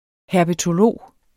Udtale [ hæɐ̯bətoˈloˀ ]